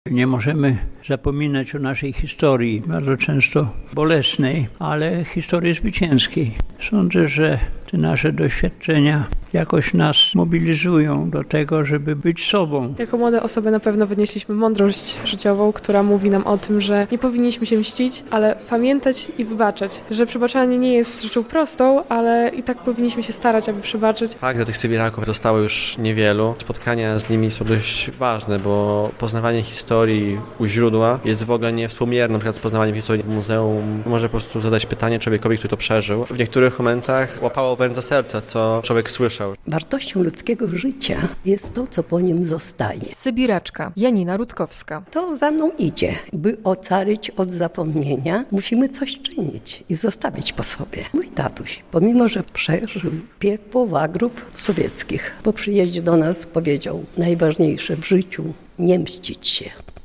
Spotkanie z Sybirakami - relacja